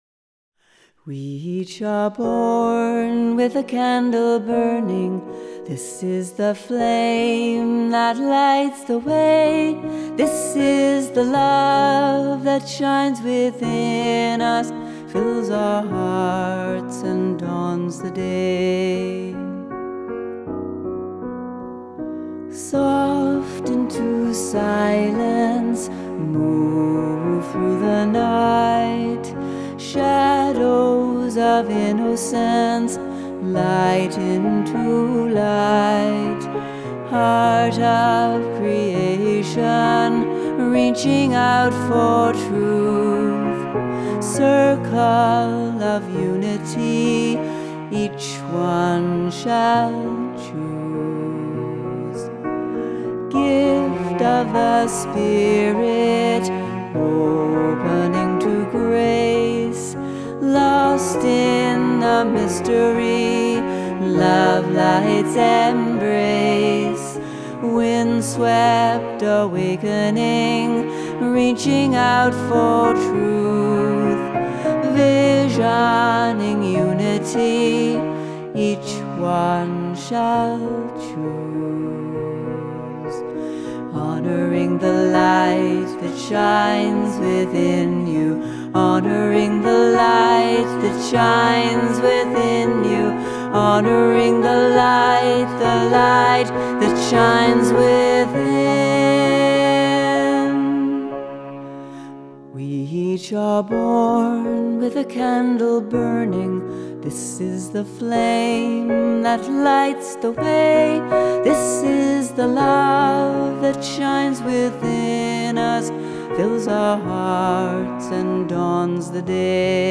Another beautiful contemporary hymn